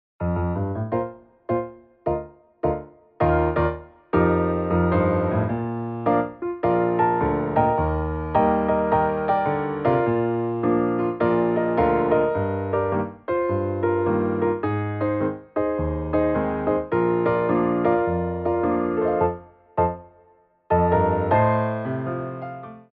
Piano Arrangements of Pop & Rock for Tap Class
SLOW TEMPO